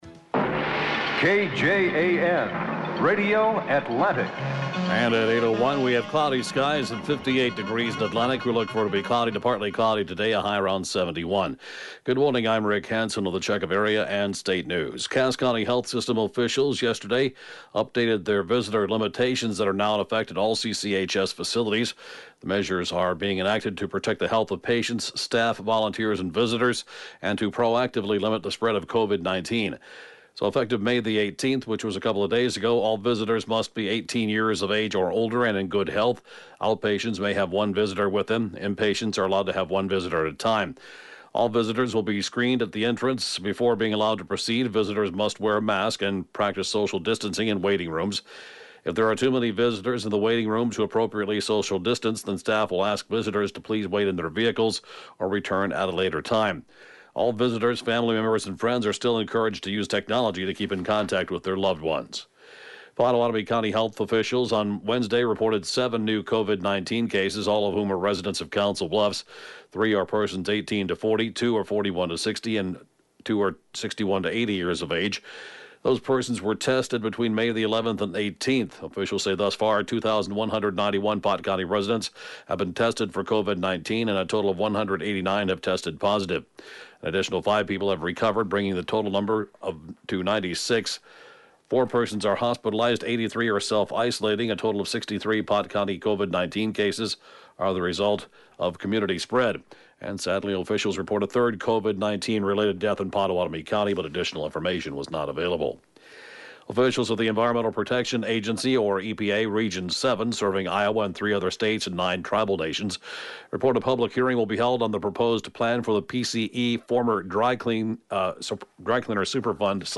(Podcast) KJAN 8-a.m. News, 5/21/20